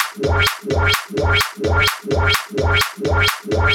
VEH1 Fx Loops 128 BPM
VEH1 FX Loop - 11.wav